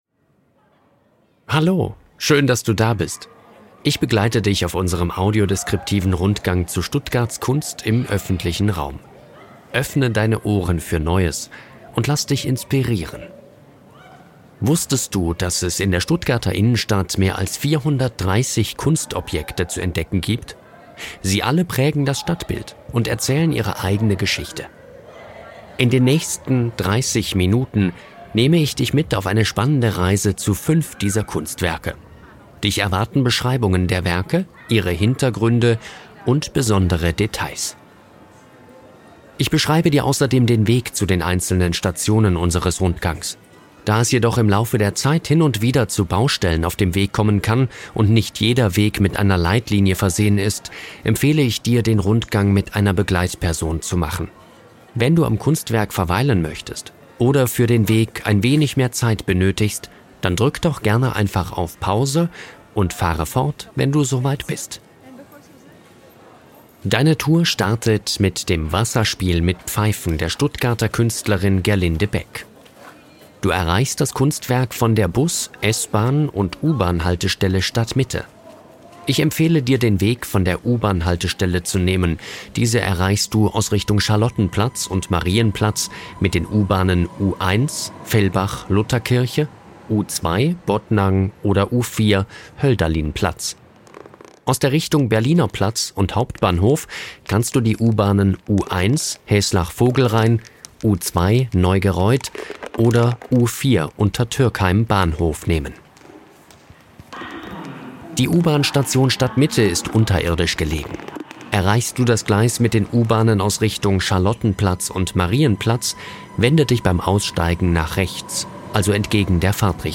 Wir haben einen audiodeskriptiven Spaziergang zu Stuttgarts Kunst im öffentlichen Raum produziert. Eine Audio-Spur im MP3-Format führt blinde und sehbehinderte Menschen zu fünf Kunstwerken - vom Rotebühlplatz über den Schlossplatz bis zum Alten Schloss.